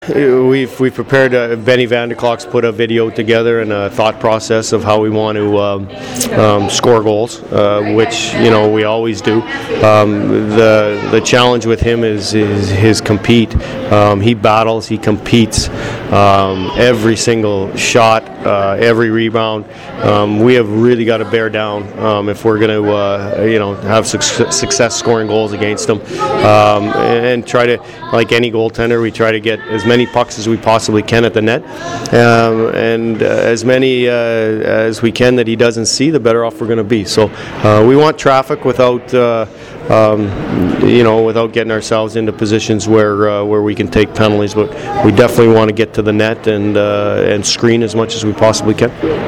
I attended practice and talked with plenty of guys on the team.